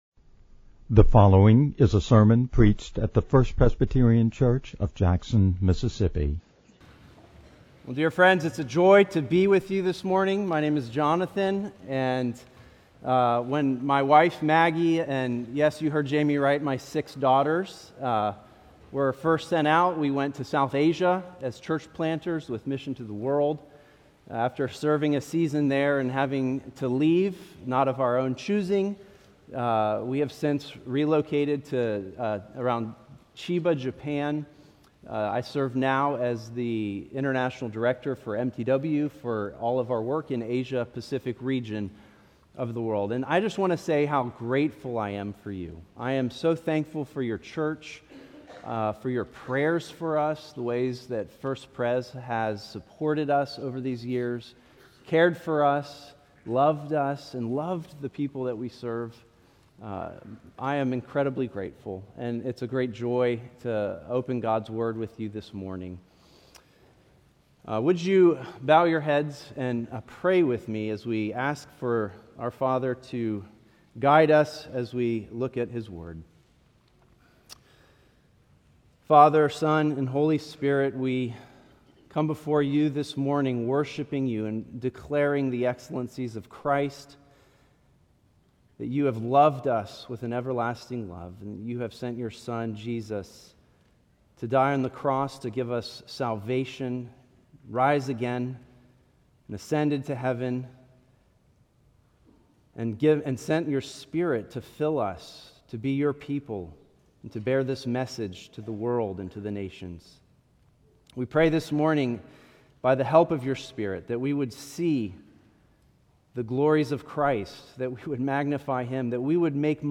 March-1-2026-Morning-Sermon-audio-with-intro.mp3